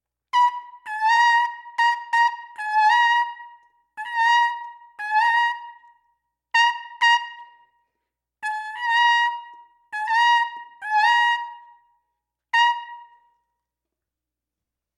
When using my ever trusty new plunger, the high C’s tended to “break” and I was having a difficult time getting all of the DuWahs to sound the same.
Below are three examples of the same passage with the third performed down an octave to demonstrate how easy the lower octave is when compared to an octave higher.
2. Plunger
2-Plunger.mp3